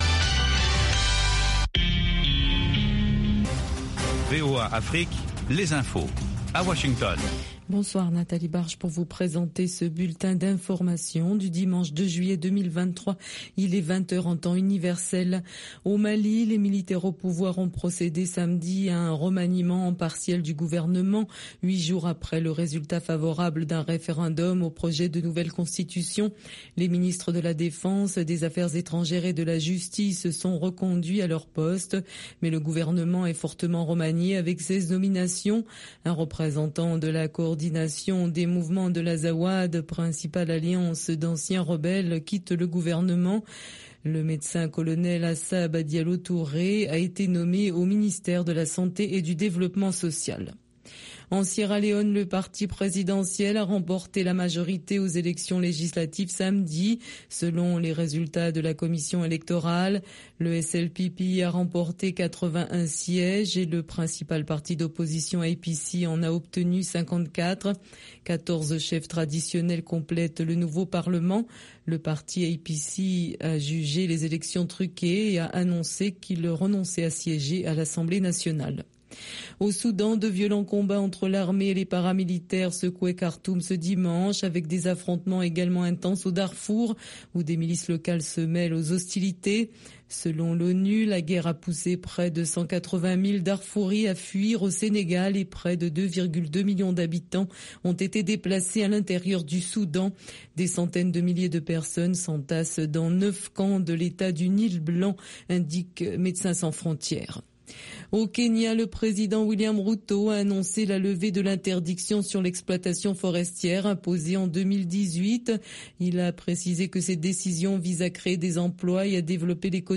Blues and Jazz Program Contactez nous sur facebook